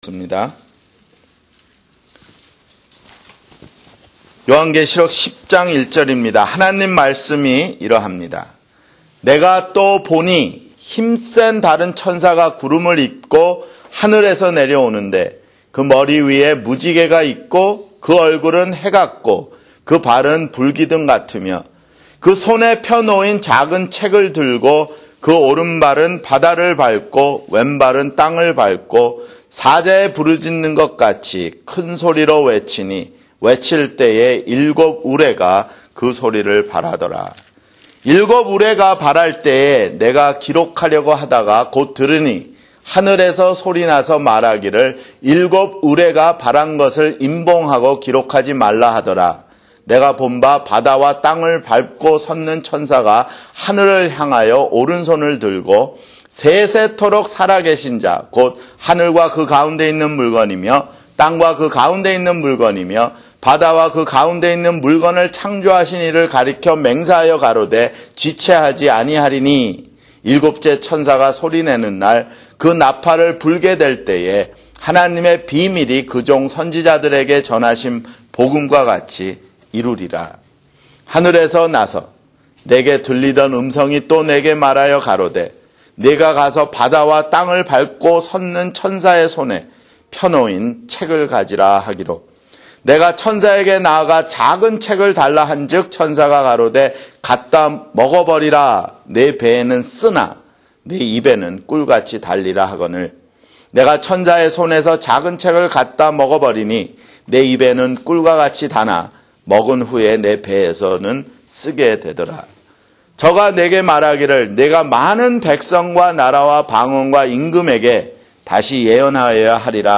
[주일설교] 요한계시록 (36)